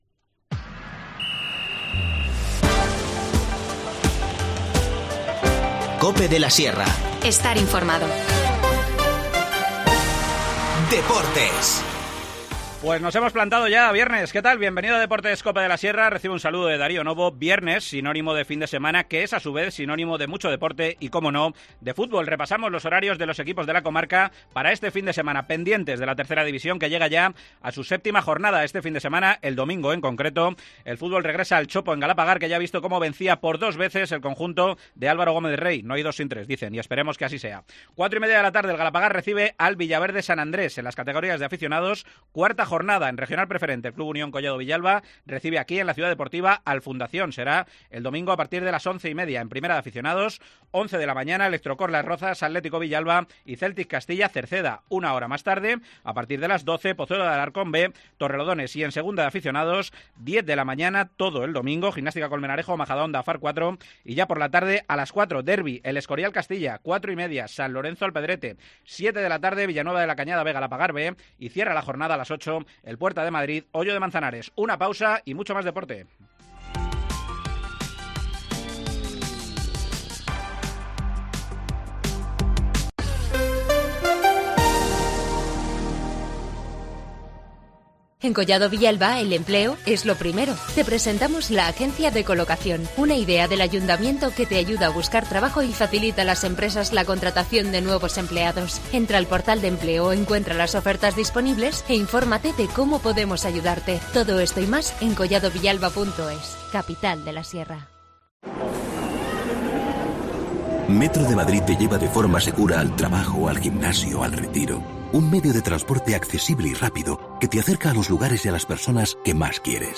El Dhaulagiri sigue resisitiéndose a Carlos Soria. El alpinista serrano de 82 años tiene que abandonar el intento por problemas físicos. Escuchamos su testimonio, cargado de emoción, en lo que quería ser un homenaje a los mayores fallecidos durante la pandemia.